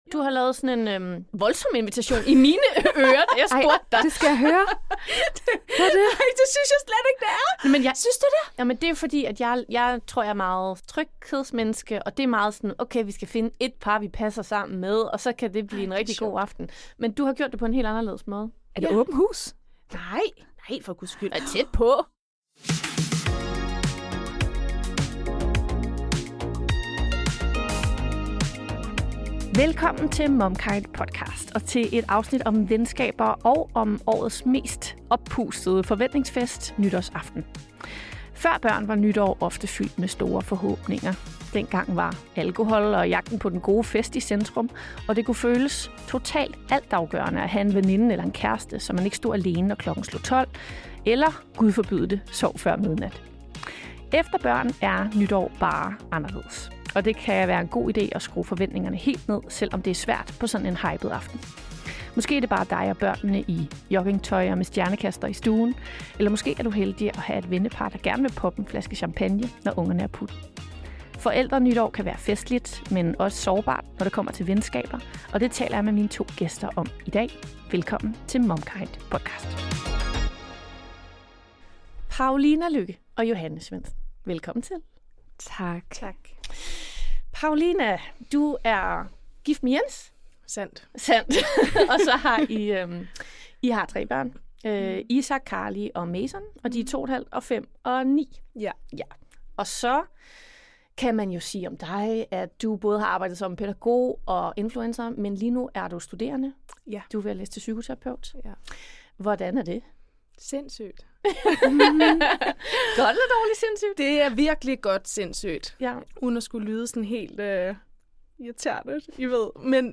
Dagens panel